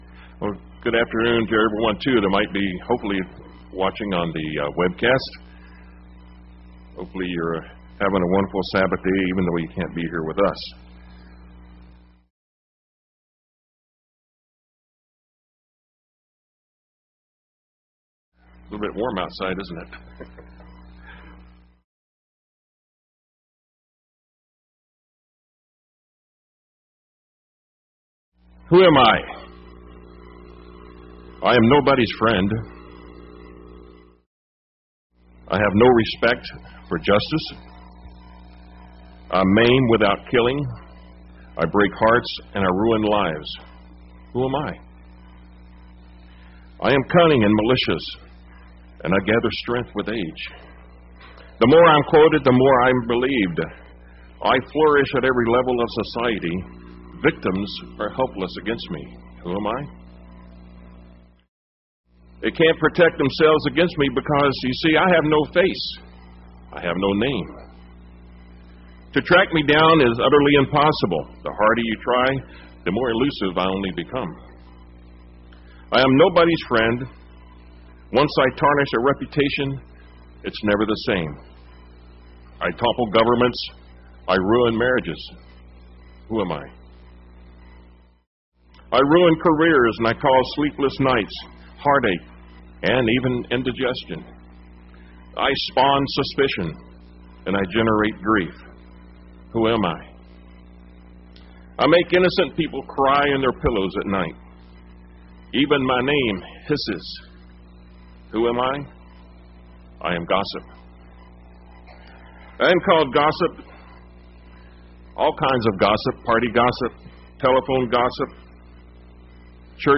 UCG Sermon Studying the bible?
Given in Tampa, FL